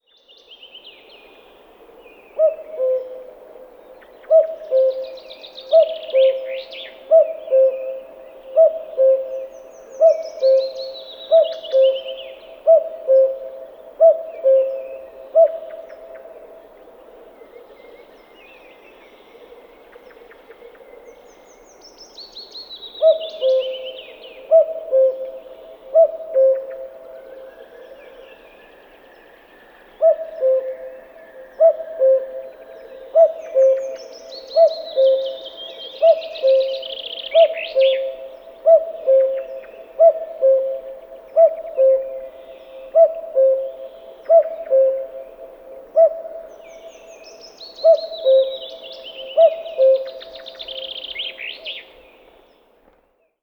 Kuckuck Ruf
Kuckuck-Ruf-Voegel-in-Europa.wav